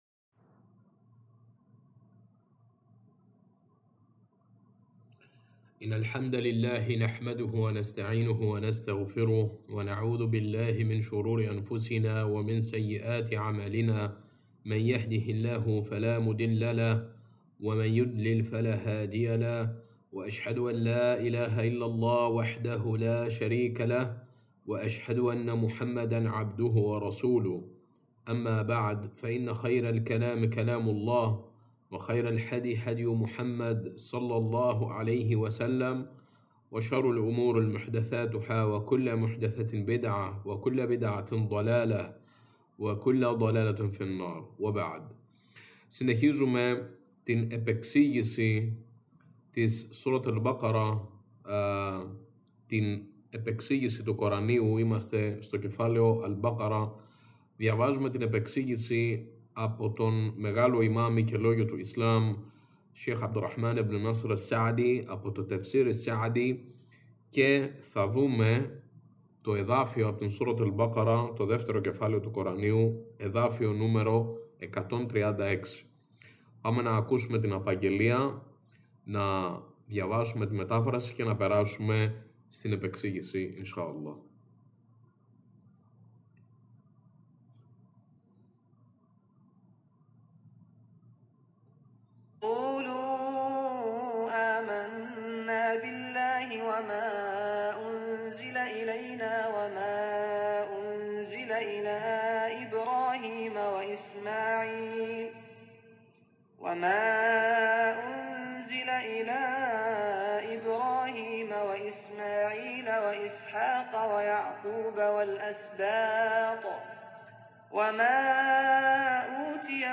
Σας καλωσορίζω σε ένα νέο και πολύ σημαντικό κύκλο Ισλαμικών μαθημάτων και παραδόσεων, τον πρώτο και μοναδικό στην Ελληνική γλώσσα για το Κοράνι. Σε αυτόν, με την άδεια του Υψίστου, θα καταπιαστούμε με την επεξήγηση του Κορανίου (Ταφσίρ) εδάφιο προς εδάφιο.